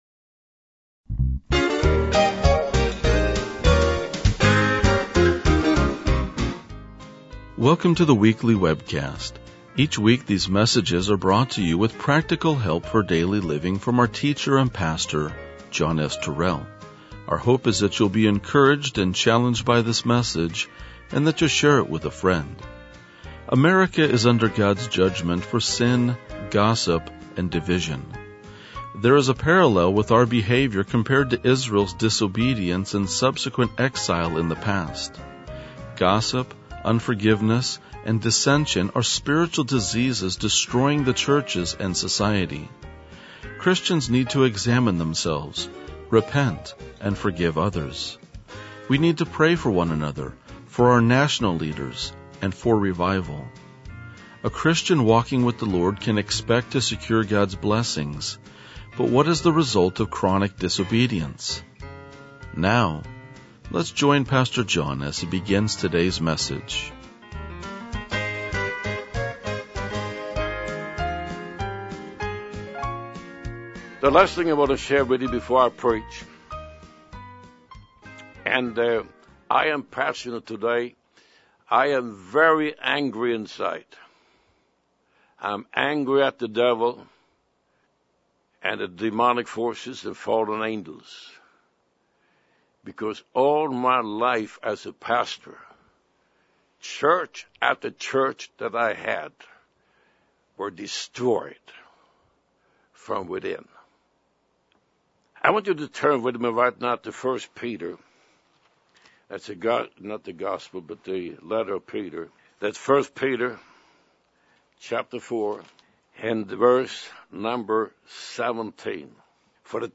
RLJ-2022-Sermon.mp3